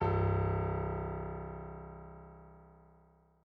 piano-sounds-dev
c0.mp3